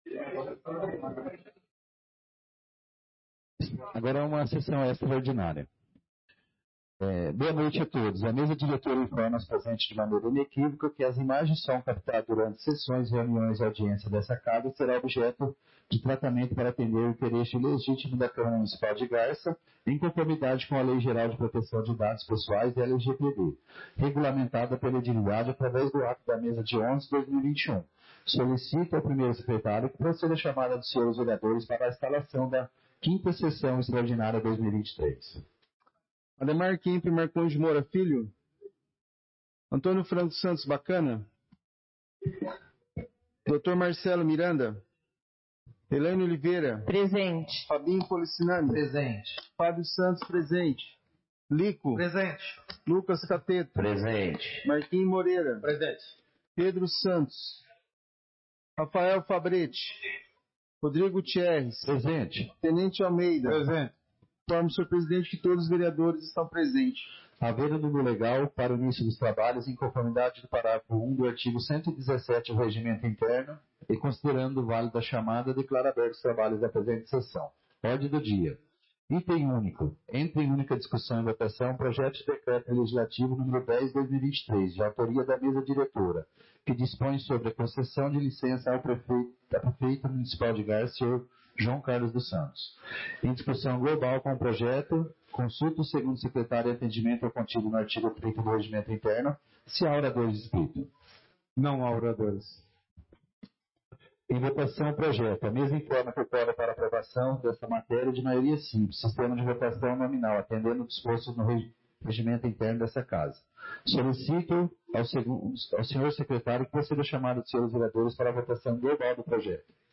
5ª Sessão Extraordinária de 2023